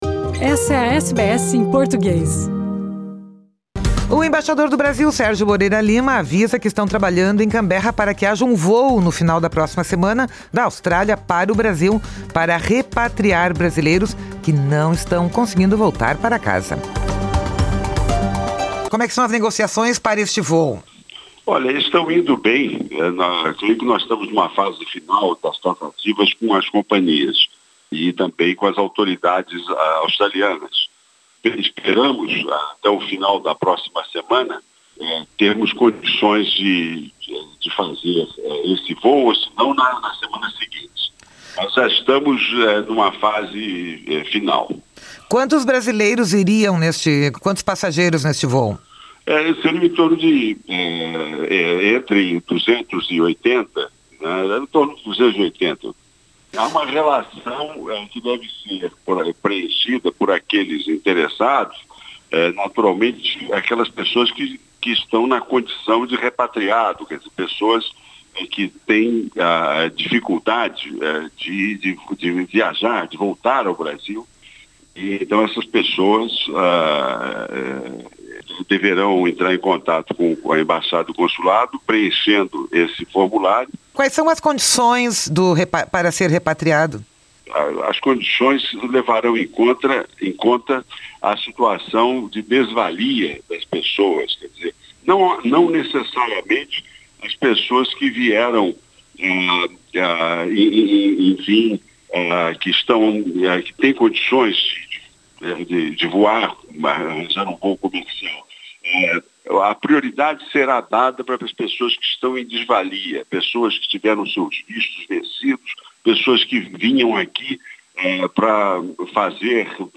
Falando à SBS em Português, o embaixador do Brasil na Austrália, Sérgio Moreira Lima, disse que este voo - fretado pela embaixada e pelo consulado-geral, em nome do governo brasileiro - tem caráter humanitário, para ajudar brasileiros que tinham a passagem de volta ao Brasil já marcada nos últimos dias ou nas próximas semanas mas que tiveram os seus voos cancelados.